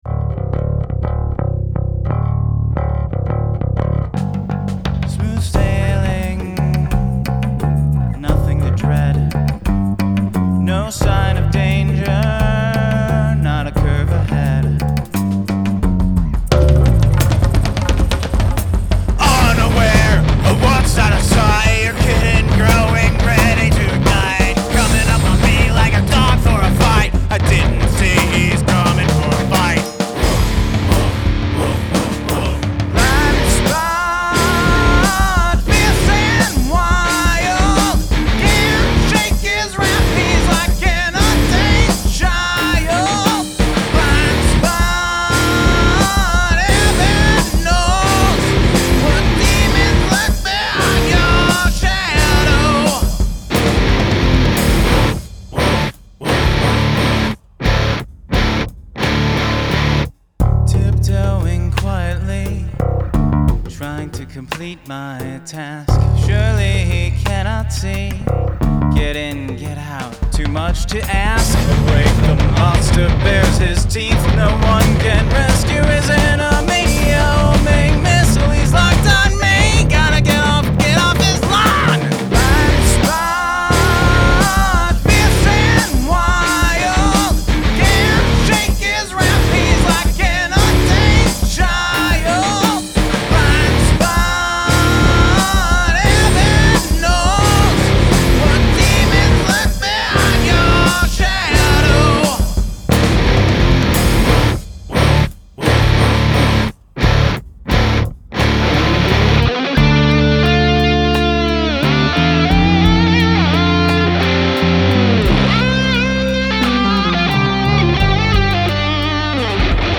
Hand percussion